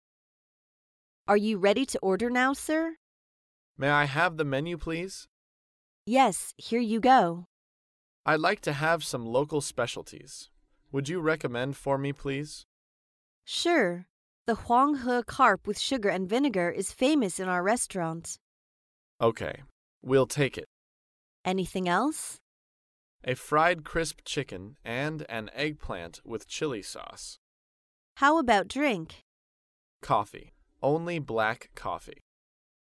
在线英语听力室高频英语口语对话 第209期:中餐点菜(2)的听力文件下载,《高频英语口语对话》栏目包含了日常生活中经常使用的英语情景对话，是学习英语口语，能够帮助英语爱好者在听英语对话的过程中，积累英语口语习语知识，提高英语听说水平，并通过栏目中的中英文字幕和音频MP3文件，提高英语语感。